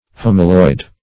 Search Result for " homaloid" : The Collaborative International Dictionary of English v.0.48: Homaloid \Hom"a*loid\ (h[o^]m"[.a]*loid), Homaloidal \Hom`a*loid"al\ (-loid"al), a. [Gr.